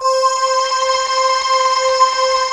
Index of /90_sSampleCDs/USB Soundscan vol.28 - Choir Acoustic & Synth [AKAI] 1CD/Partition C/16-NIMBUSSE